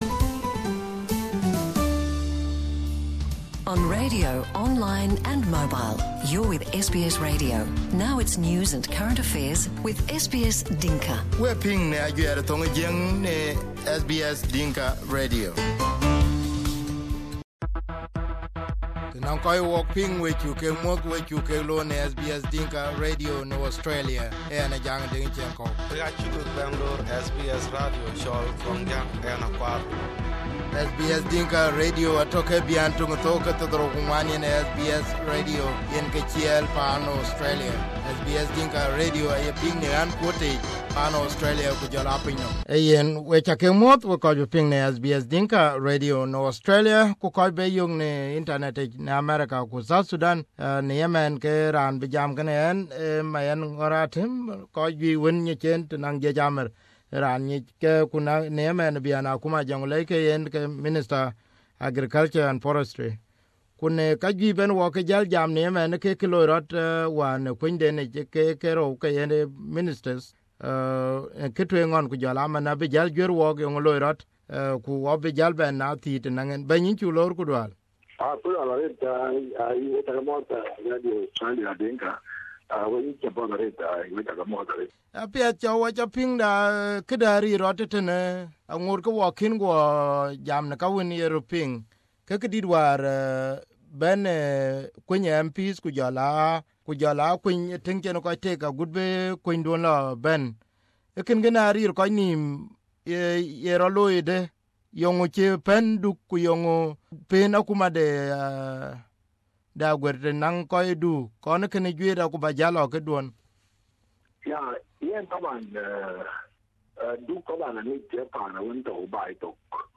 In this interview, Mayen explains the situation that surrounding the complaints of the Duk community and also answers some questions about continuing his job as minister despite the call to step aside in protest. Here is the interview in Dinka.